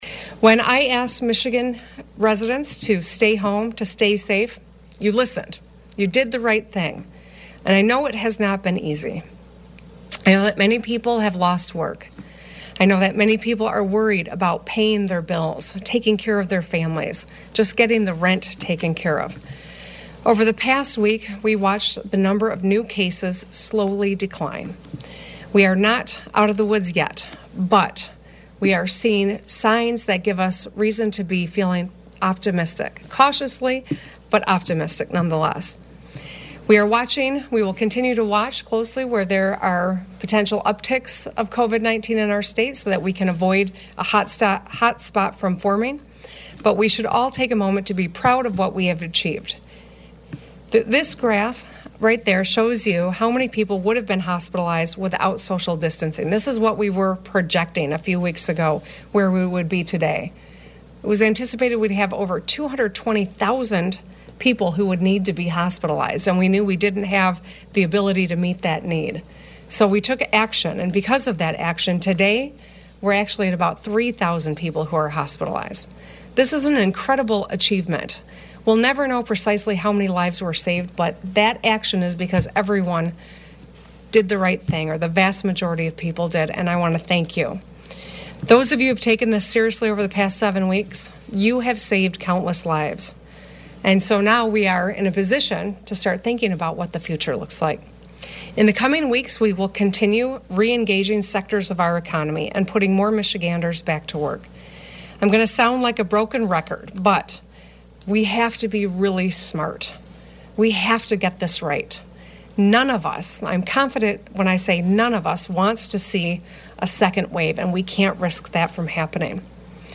CLICK TO HEAR GOVERNOR’S COMMENTS FROM MONDAY AFTERNOON